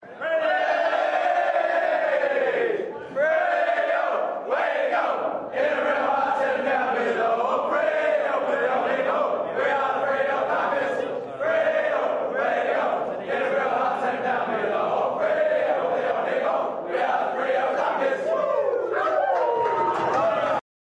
Listen to the players sing the team song after their win over Essendon